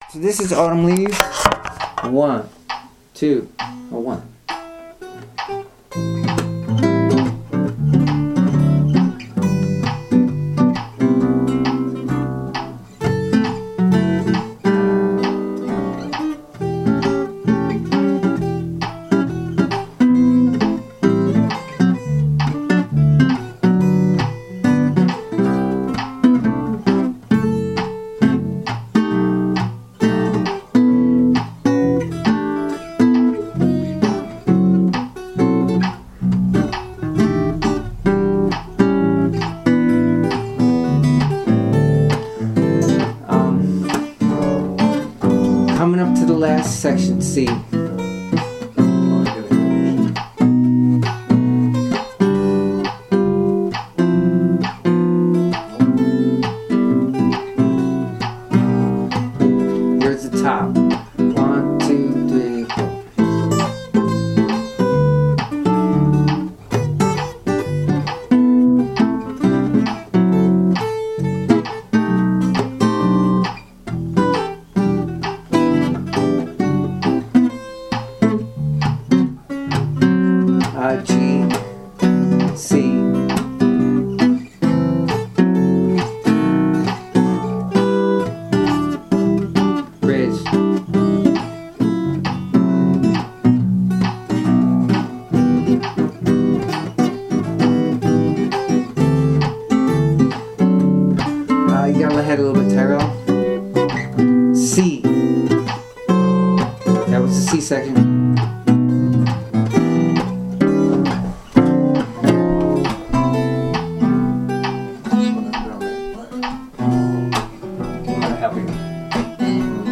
Jazz comping - Guitar Lessons in Ithaca, NY
jazz-comping-1.mp3